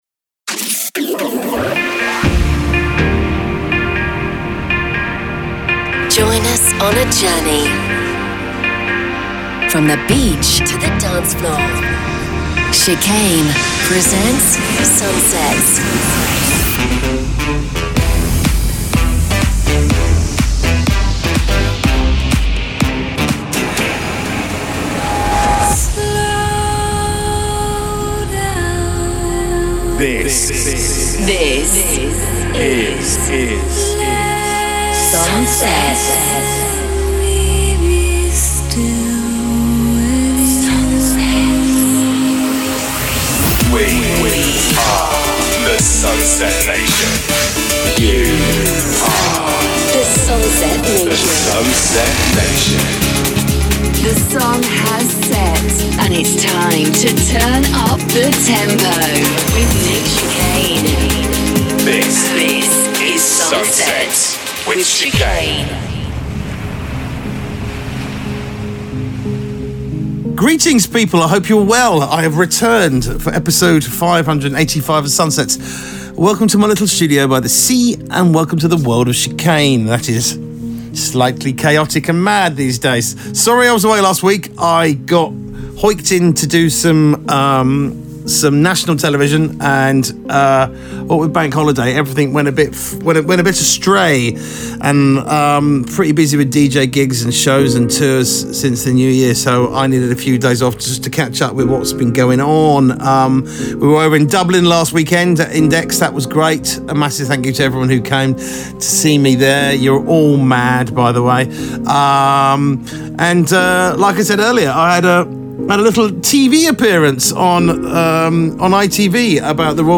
From the beach to the dancefloor...